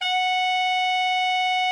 TENOR 35.wav